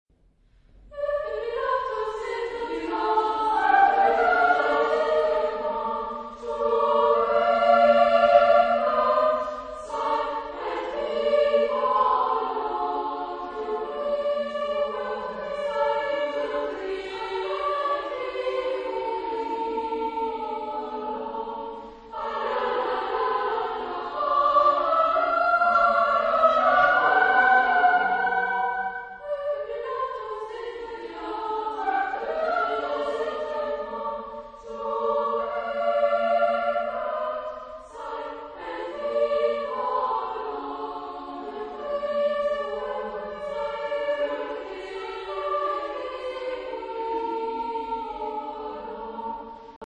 Genre-Stil-Form: Madrigal ; Tanzlied ; Barock ; Renaissance ; weltlich
Chorgattung: SSA  (3 Frauenchor Stimmen )
Instrumente: Flöte (ad lib)
Aufnahme Bestellnummer: 7. Deutscher Chorwettbewerb 2006 Kiel